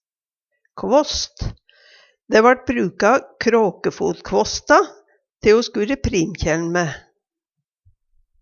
kråkefotkvåst - Numedalsmål (en-US)